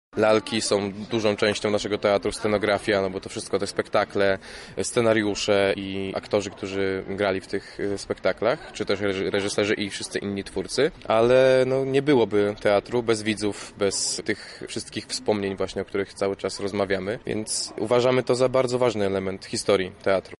aktor